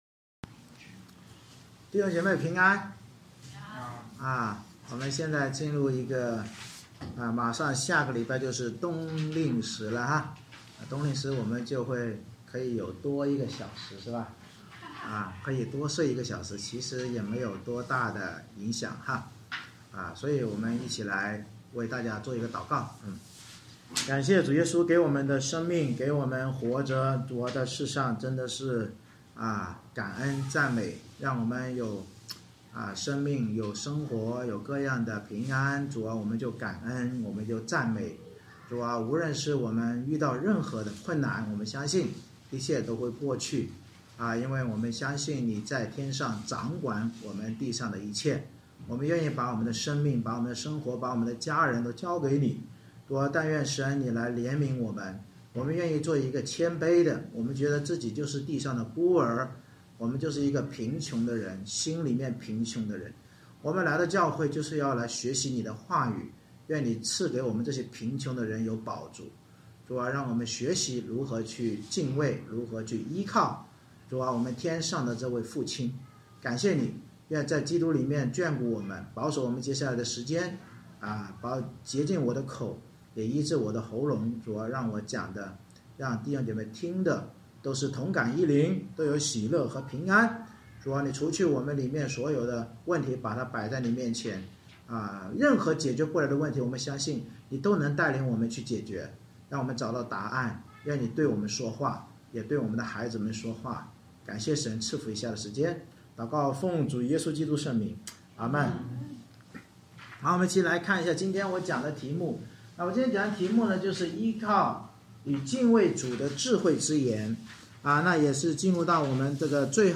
箴言22：19-23：11 Service Type: 主日崇拜 借着智慧人言论的引言和第一部分之教导，警告我们依靠神的首要是不可劳碌求财而欺压抢夺困苦人和孤儿，因基督是他们的救主。